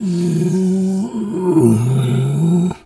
pgs/Assets/Audio/Animal_Impersonations/bear_roar_soft_10.wav at master
bear_roar_soft_10.wav